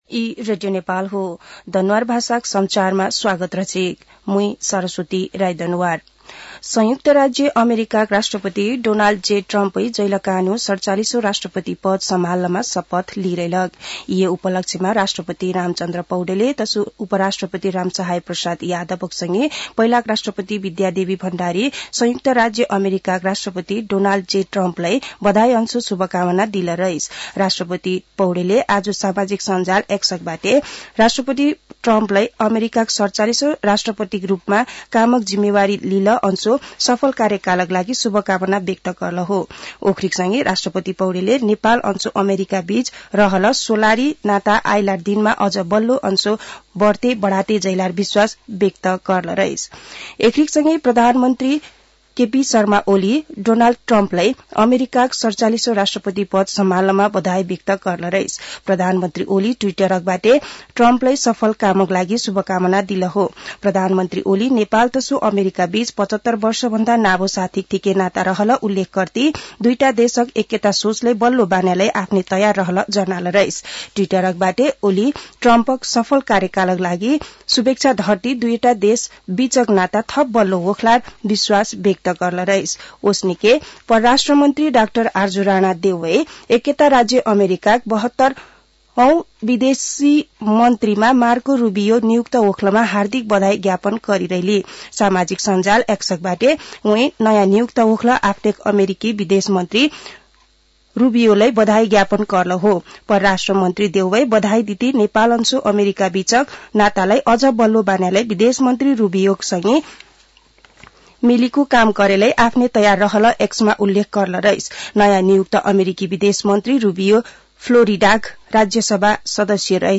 दनुवार भाषामा समाचार : ९ माघ , २०८१
Danuwar-news-7.mp3